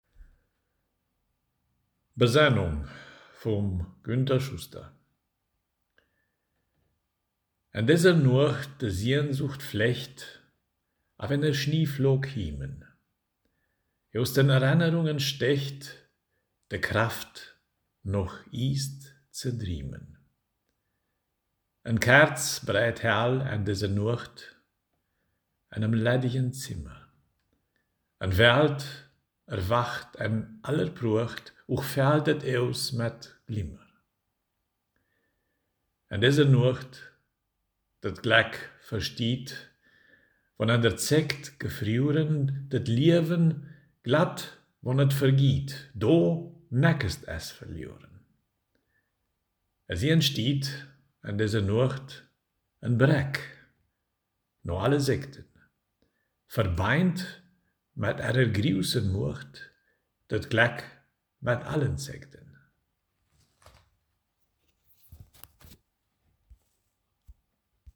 Ortsmundart: Mediasch